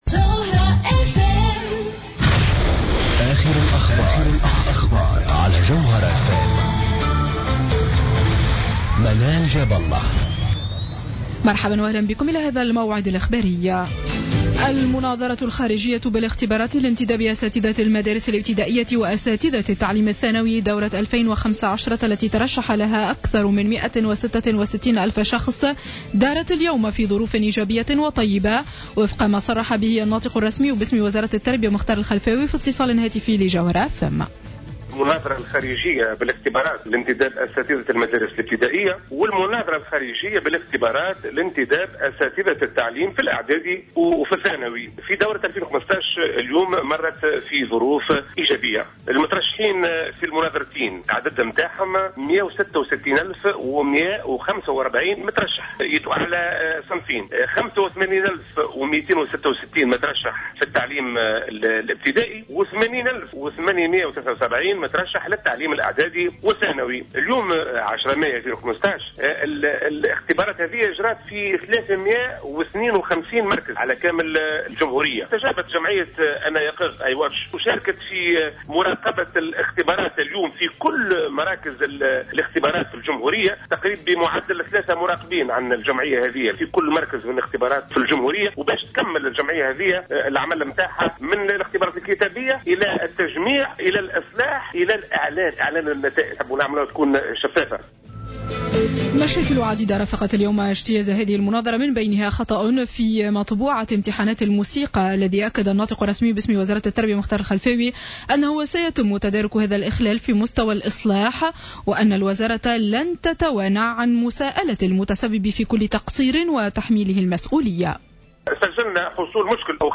نشرة أخبار السابعة مساء ليوم الأحد 10 ماي 2015